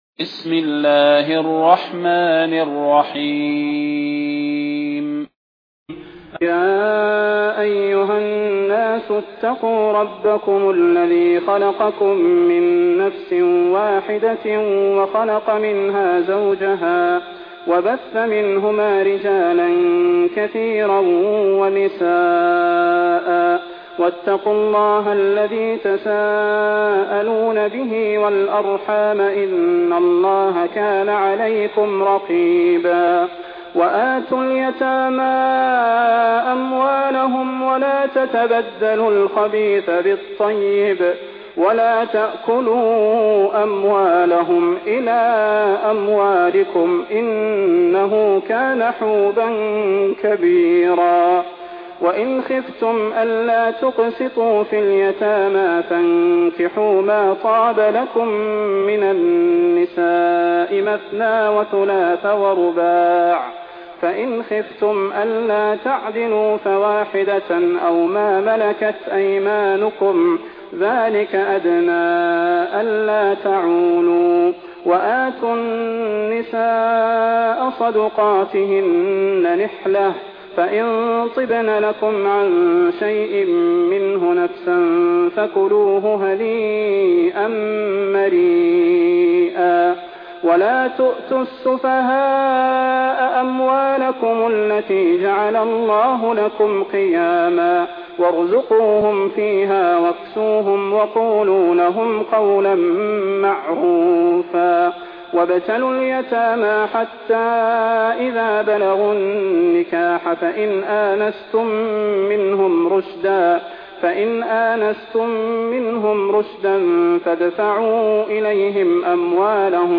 المكان: المسجد النبوي الشيخ: فضيلة الشيخ د. صلاح بن محمد البدير فضيلة الشيخ د. صلاح بن محمد البدير النساء